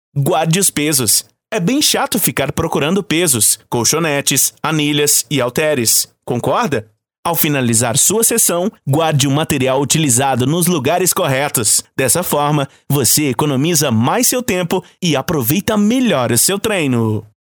Anúncios Cortesia